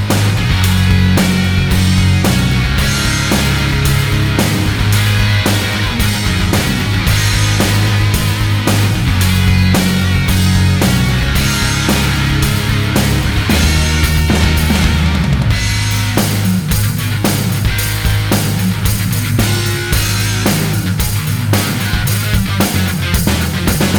no Backing Vocals Rock 3:52 Buy £1.50